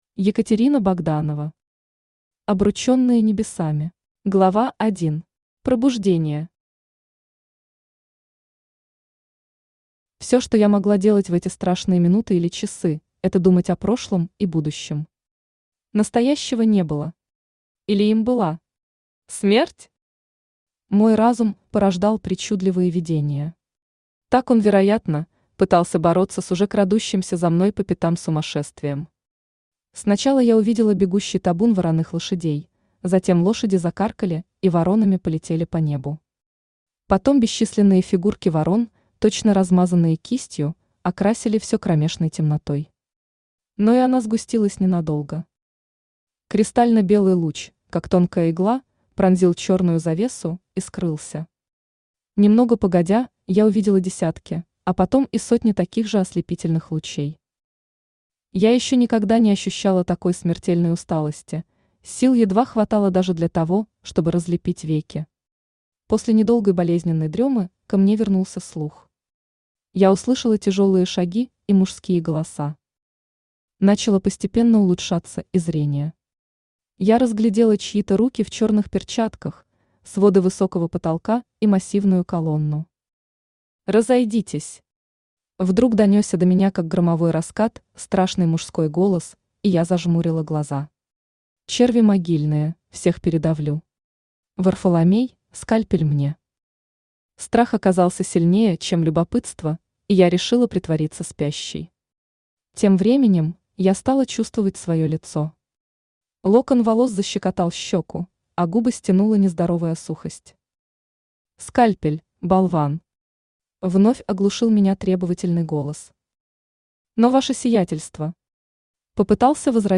Аудиокнига Обручённые небесами | Библиотека аудиокниг